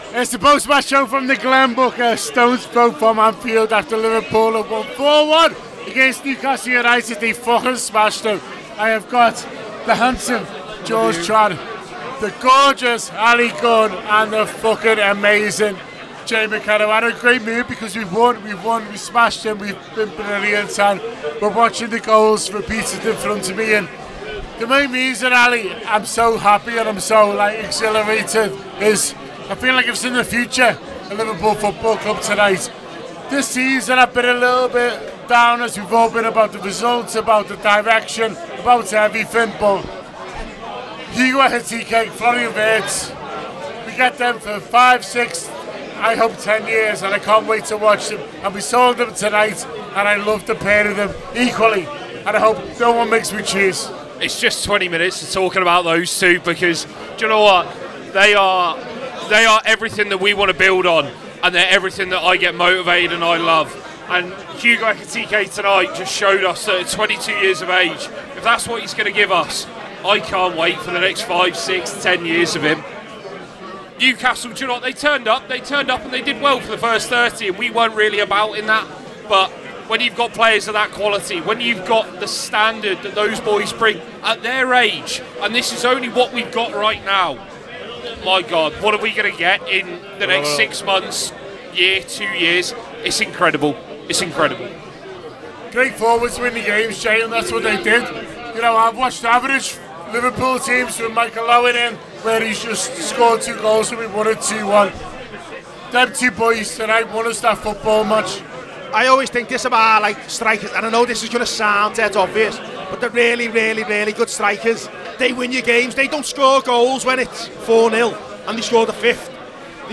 Liverpool 4-1 Newcastle: Post Match Show Reaction Podcast